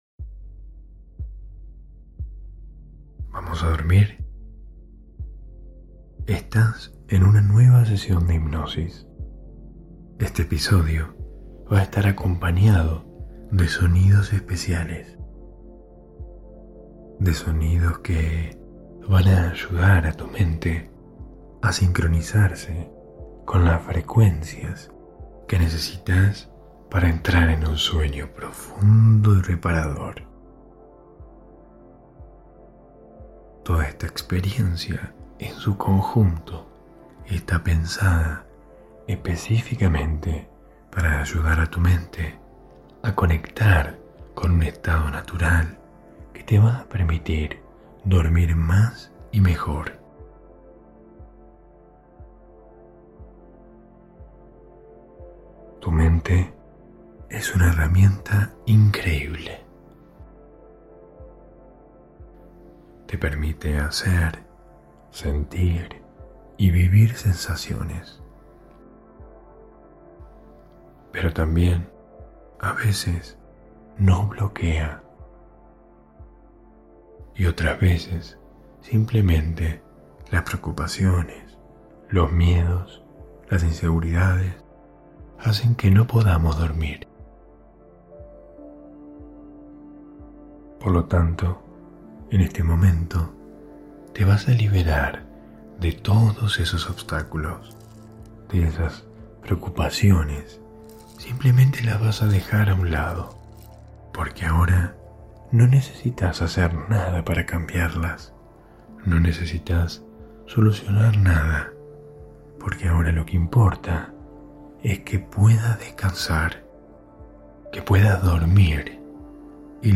Hipnosis para Dormir 2021